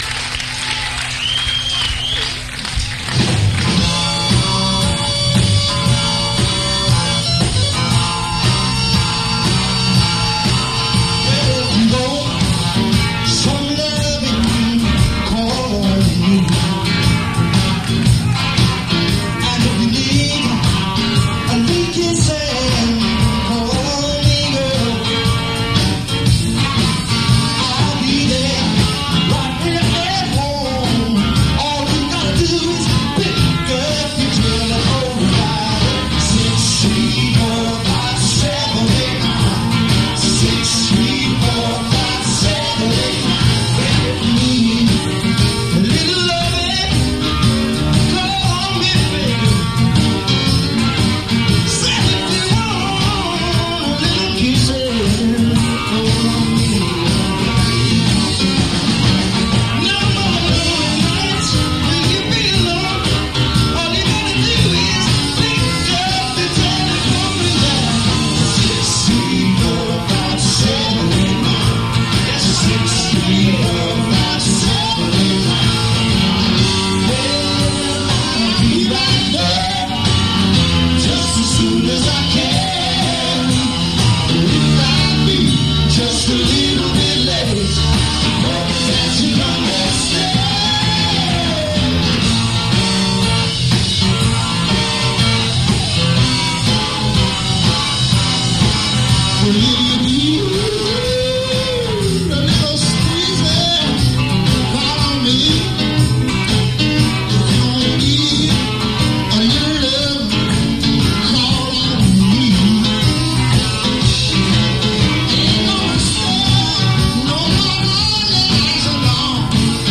Attn: muddy and tinny sound